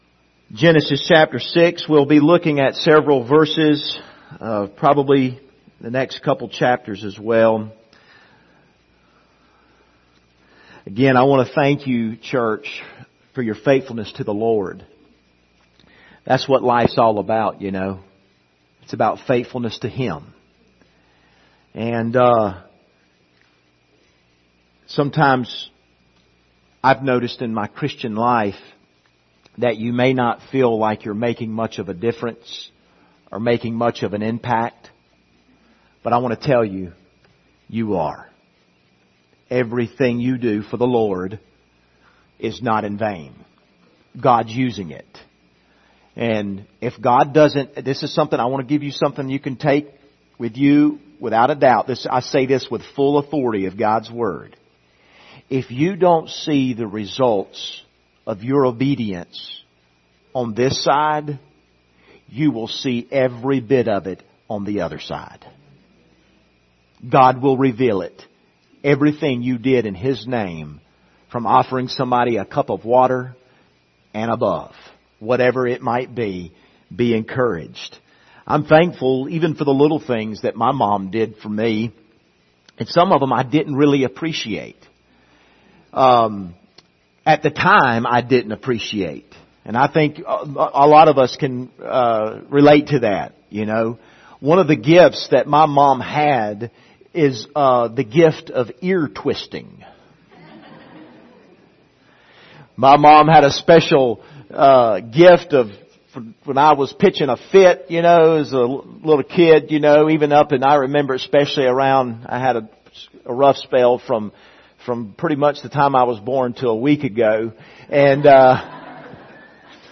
Genesis 6 Service Type: Sunday Morning Topics